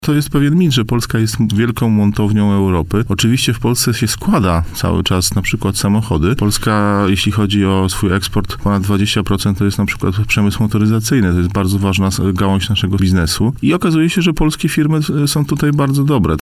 – Zmienia się przede wszystkim rola Polski w europejskim przemyśle – powiedział w poranku „Siódma9” na antenie Radia Warszawa prezes Agencji Rozwoju Przemysłu Marcin Chludziński.